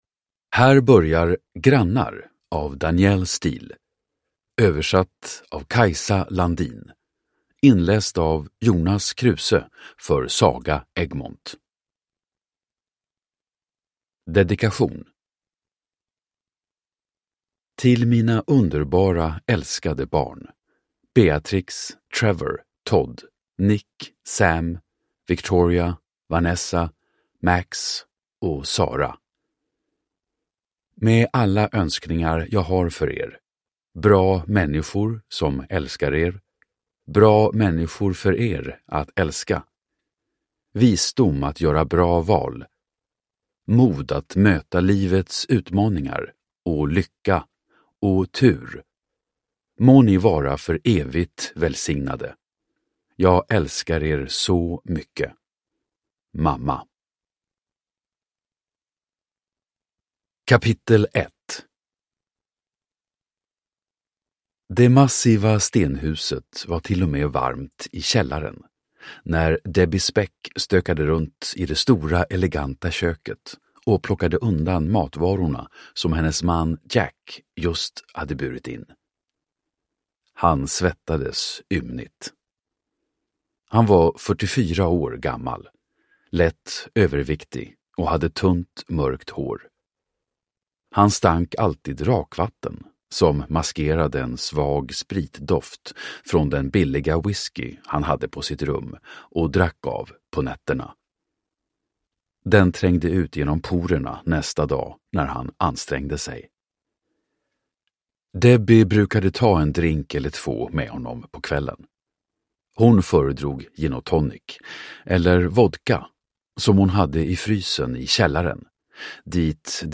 Grannar – Ljudbok